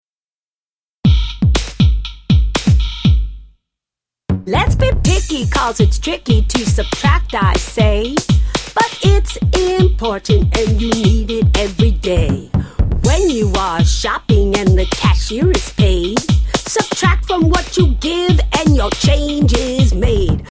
Animal Subtraction Rap Lyrics and Sound Clip